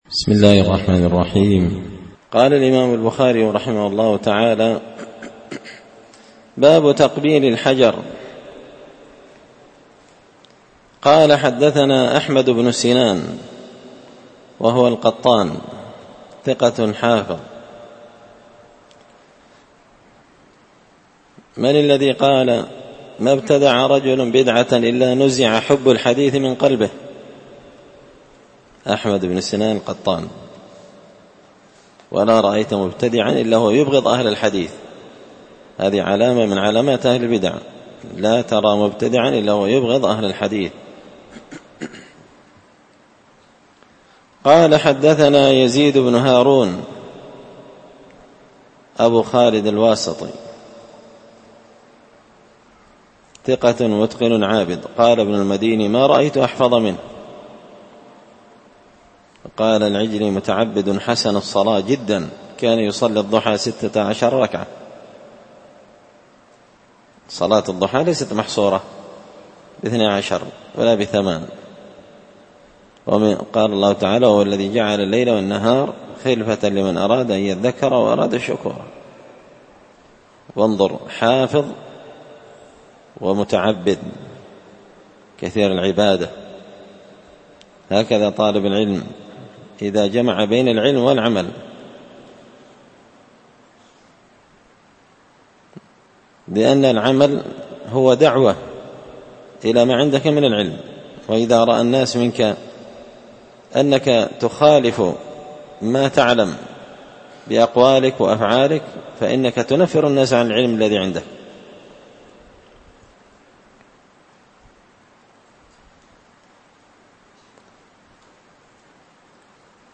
كتاب الحج من شرح صحيح البخاري – الدرس 53
دار الحديث بمسجد الفرقان ـ قشن ـ المهرة ـ اليمن